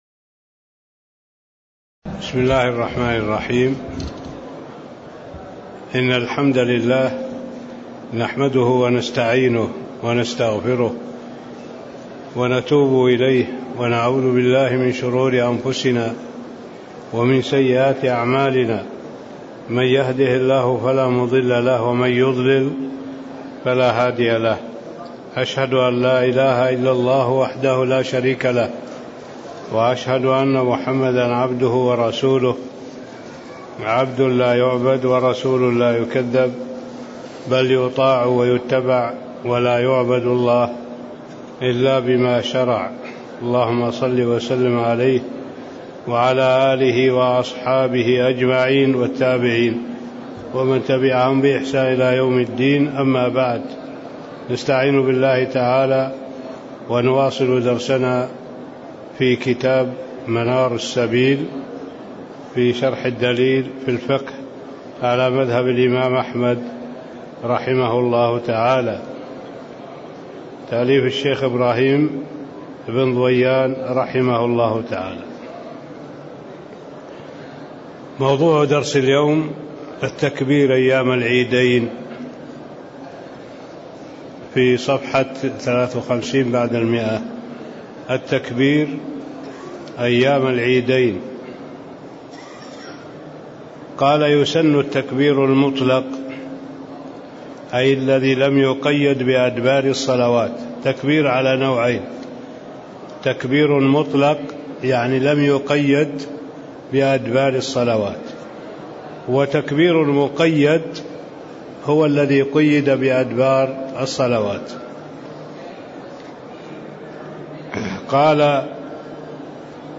تاريخ النشر ٢١ رجب ١٤٣٦ هـ المكان: المسجد النبوي الشيخ